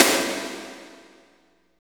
52.05 SNR.wav